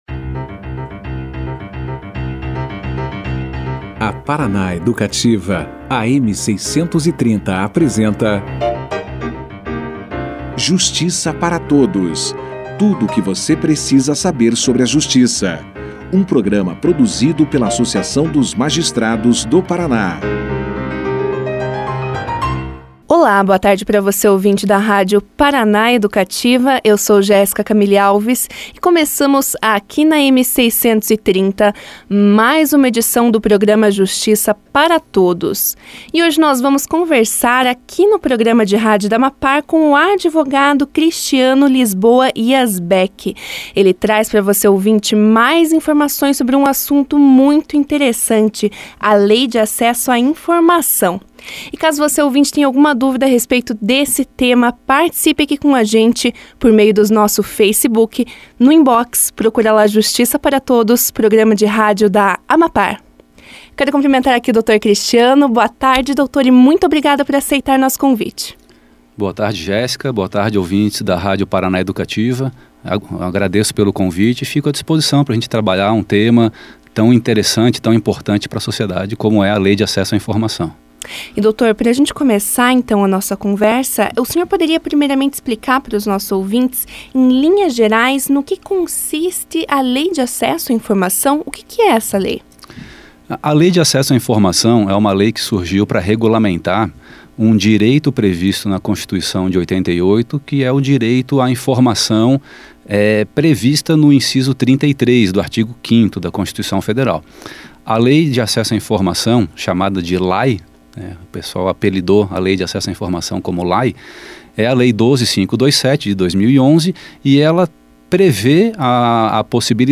Lei de acesso à informação foi o tema debatido no Justiça para Todos, desta segunda-feira (10).